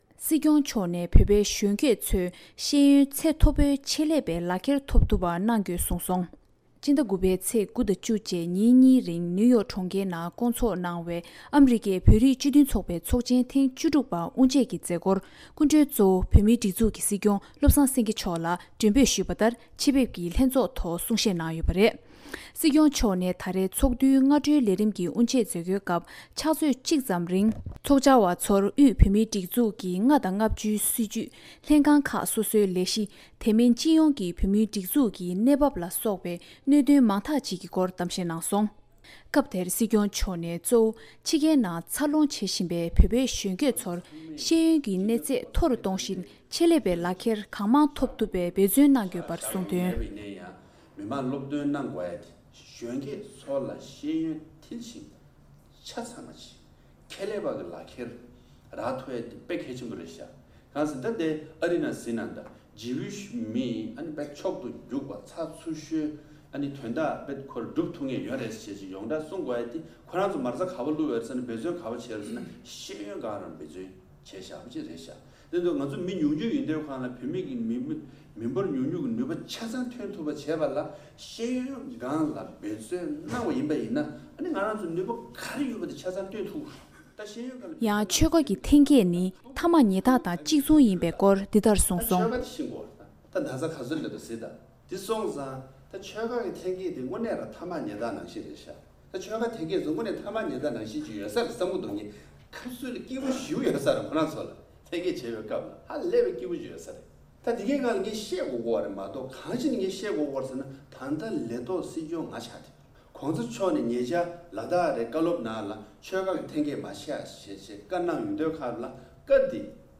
སྲིང་སྐྱོང་མཆོག་ནས་བྱང་ཨ་རིའི་བོད་རིགས་སྤྱི་མཐུན་ཚོགས་པའི་ཚོགས་ཆེན་ཐེངས་༡༦པའི་ཐོག་གསུང་བཤད་གནང་བ། སྒྲ་ལྡན་གསར་འགྱུར།